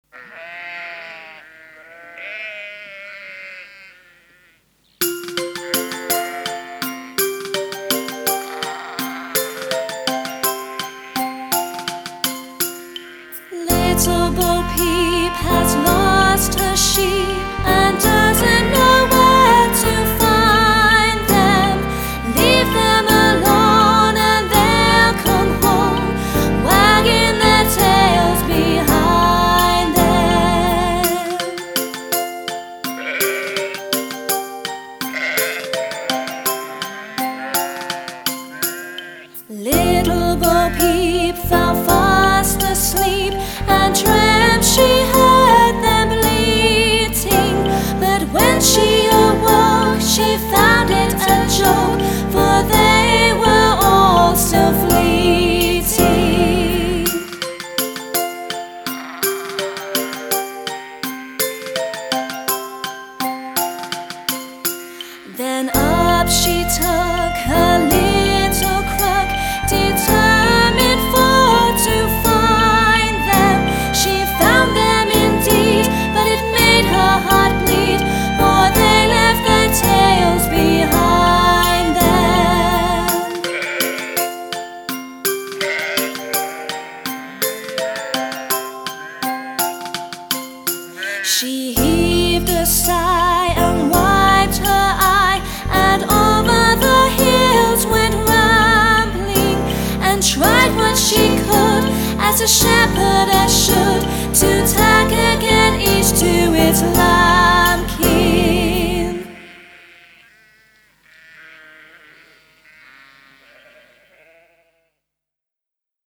• Категория: Детские песни
Колыбельные на английском